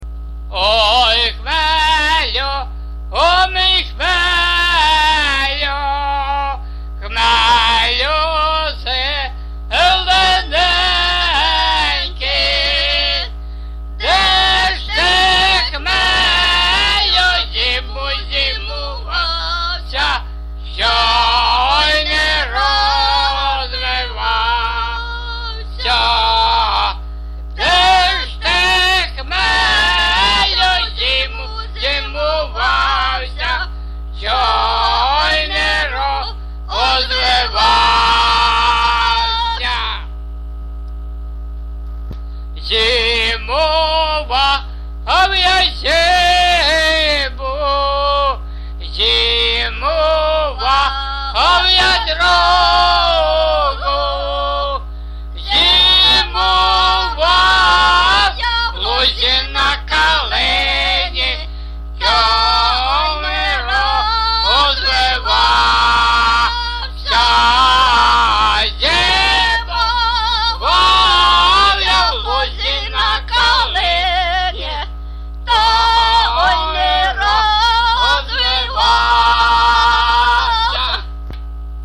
ЖанрПісні з особистого та родинного життя
Місце записус. Нижні Рівні, Чутівський район, Полтавська обл., Україна, Слобожанщина